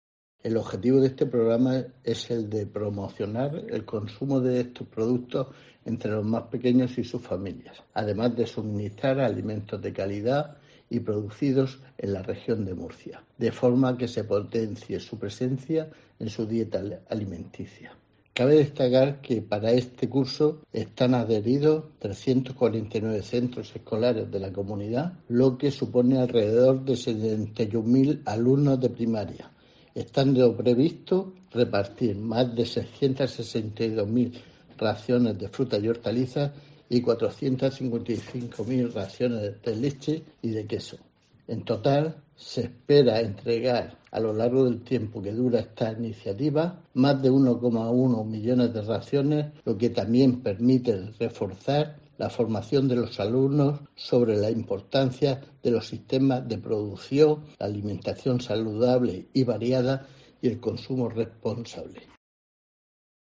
Francisco González Zapater, secretario general de la Consejería de Agricultura
Así lo destacó el secretario general de la Consejería de Agua, Agricultura, Ganadería y Pesca, Francisco González Zapater, durante la clausura del proyecto ‘Alimentación Sostenible del Territorio Sierra Espuña’, celebrada esta semana en Pliego.